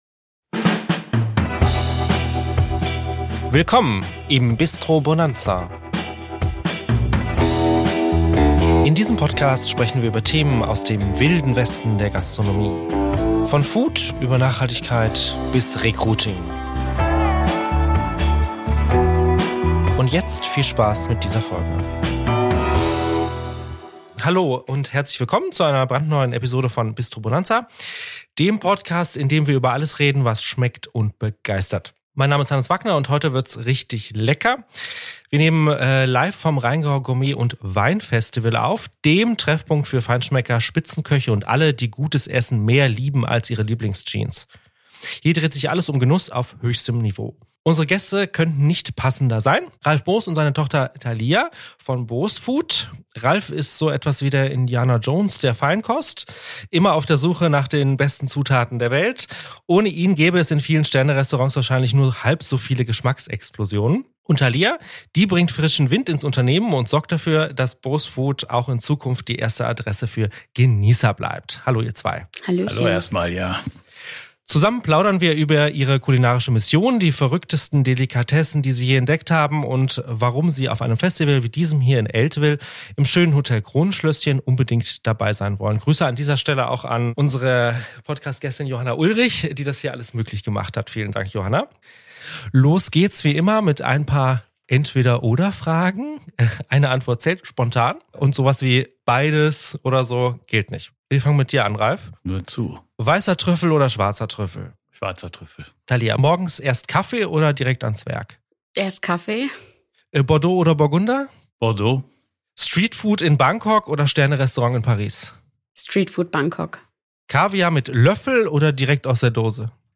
Für diese Spezialfolge haben wir uns stilecht ins Rheingau Gourmet & Wein Festival eingeklinkt und die Aufnahmetechnik kurzerhand in ein Hotelzimmer mit Kronleuchter verlegt.